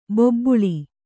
♪ bobbuḷi